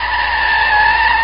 TireScreech.wav